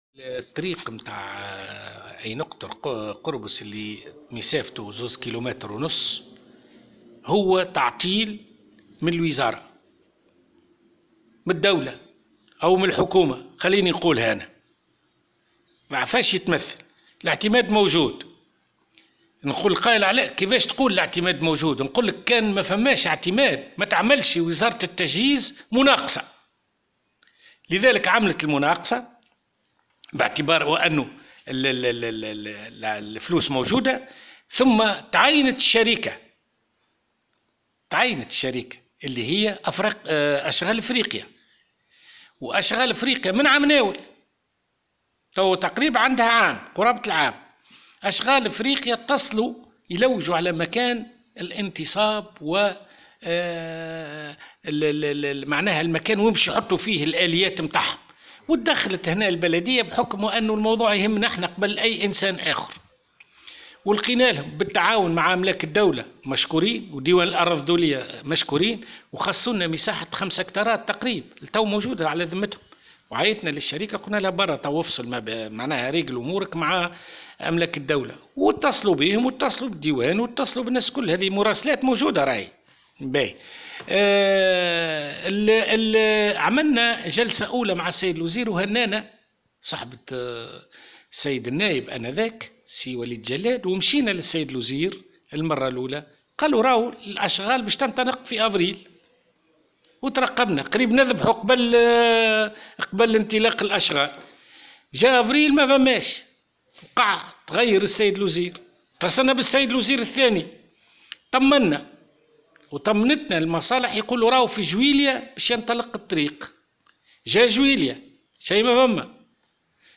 طالب رئيس بلدية قربص محمد الحمروني السلطات في نداء استغاثة عبر "الجوهرة أف أم" اليوم الخميس، بضرورة الإسراع في اصدار الإذن بالانطلاق في انجاز الطريق المؤدية الى قربص.